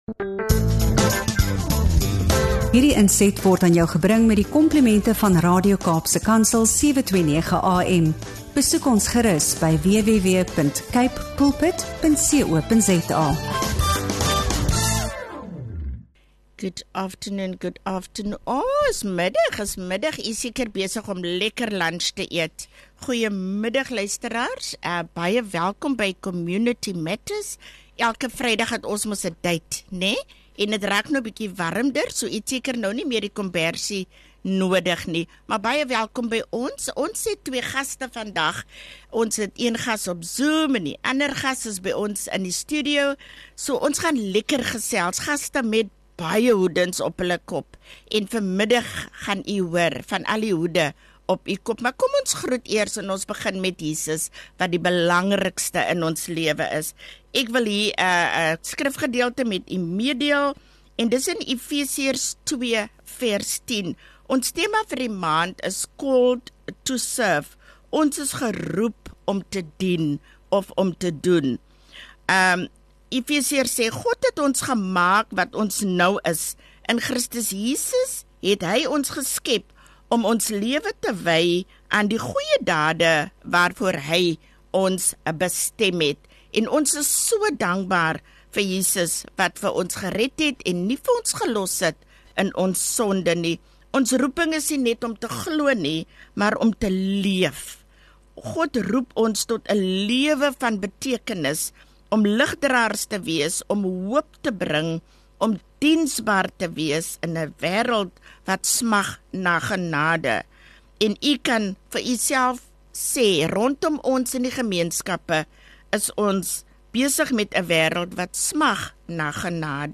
met dinamiese vroue van geloof